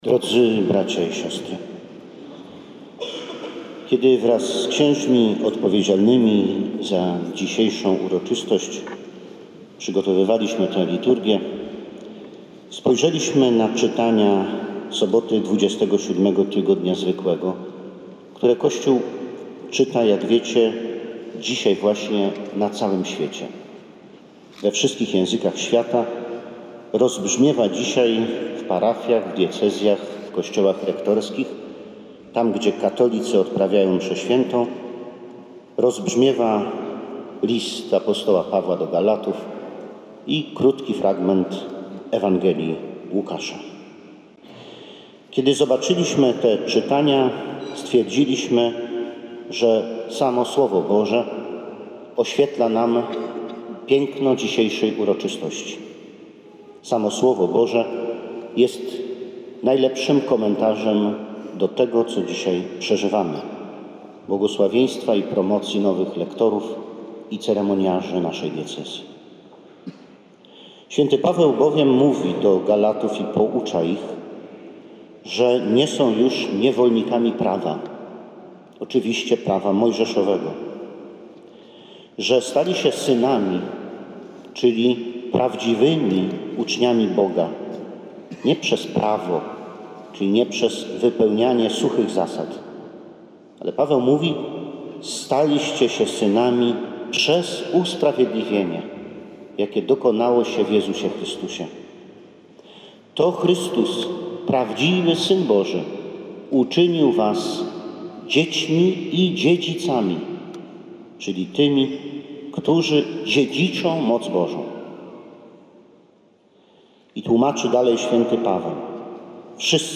Biskup pomocniczy diecezji warszawsko-praskiej uczestniczył w uroczystości mianowania nowych lektorów i ceremoniarzy w Kościele Konkatedralnym na Kamionku.
Cała homilia bp. Jacka Grzybowskiego: